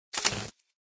SCableCut.ogg